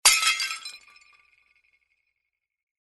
Звуки разбитого стекла, посуды
На этой странице собраны разнообразные звуки разбитого стекла и посуды: от легкого звона бокалов до резкого грохота падающих тарелок.